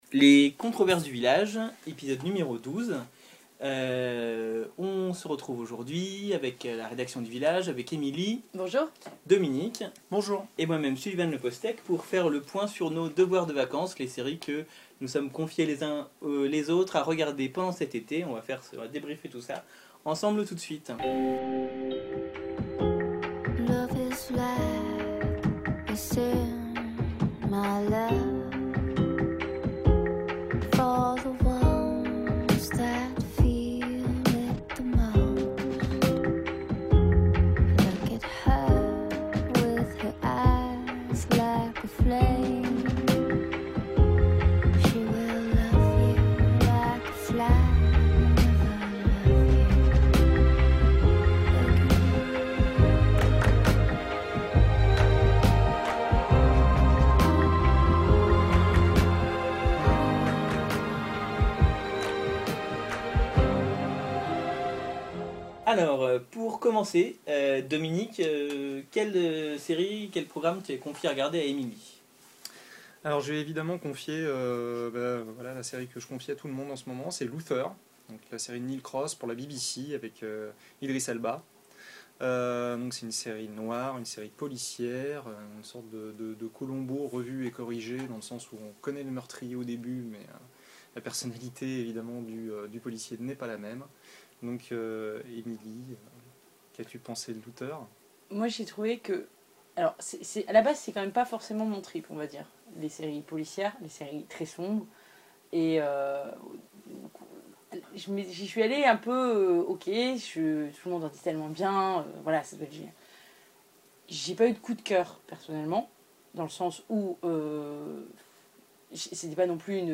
L’heure est maintenant venue pour les membres de la rédaction de livrer leurs impressions et d’en débattre.